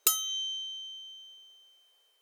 Add crystal collect sound
ding.wav